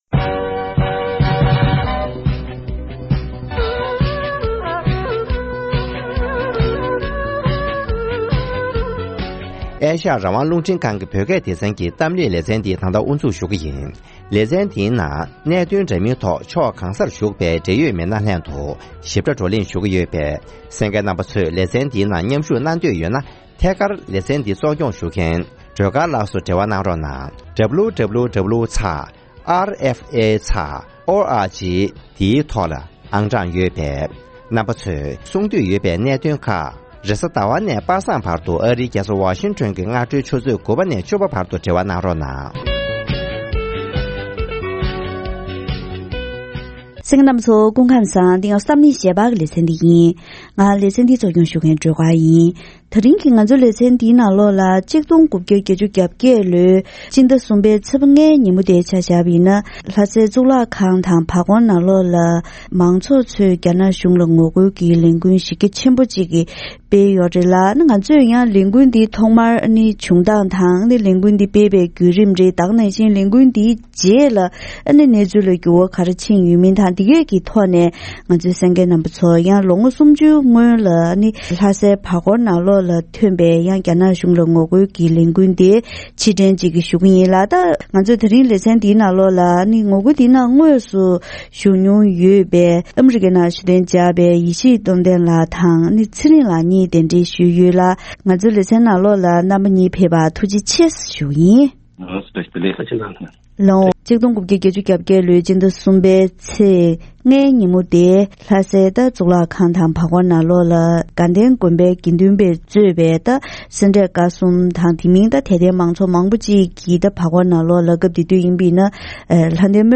ཐེངས་འདིའི་གཏམ་གླེང་ཞལ་པར་ལེ་ཚན་ནང་།